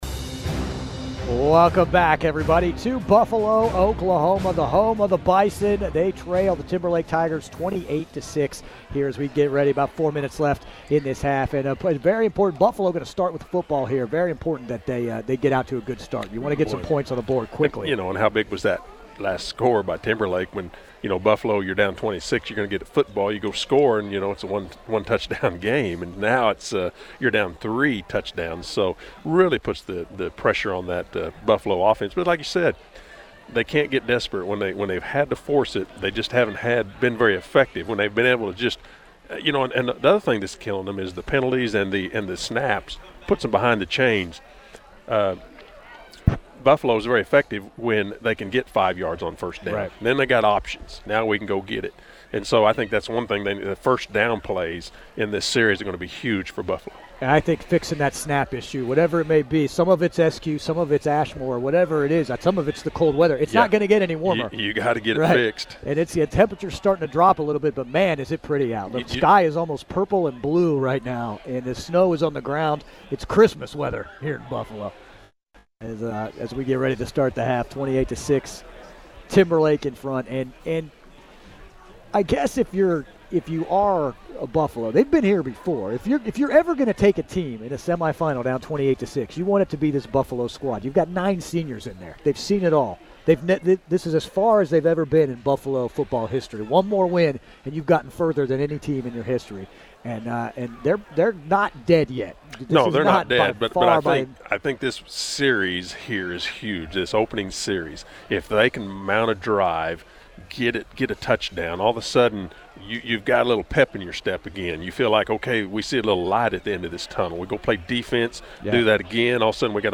You can replay the game right here on K101!